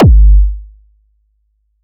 Index of /archive/TRUTH-HZ/2023_TRUTH-HZ_USB/Sample Packs/Crowdsourced Sample Pack/Drum Kit/808s & Subs